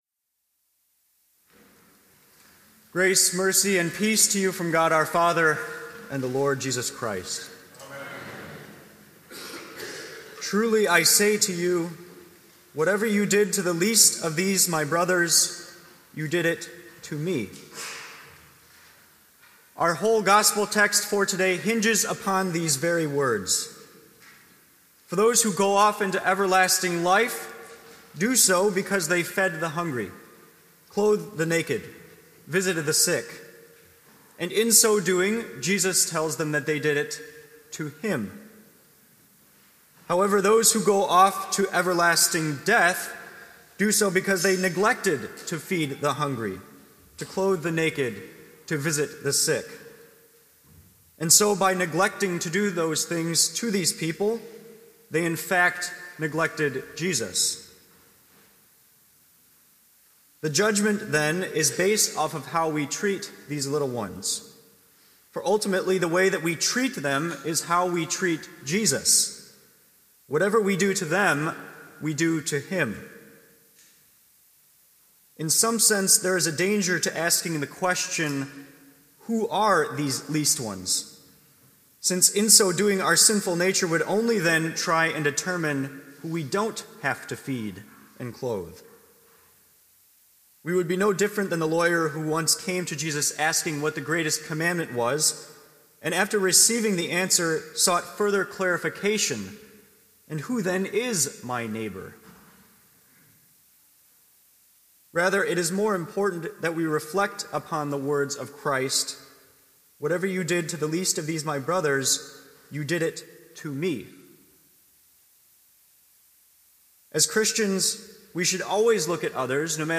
The Second-to-Last Sunday of the Church Year